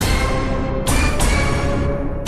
chan chan chan yingo Meme Sound Effect
This sound is perfect for adding humor, surprise, or dramatic timing to your content.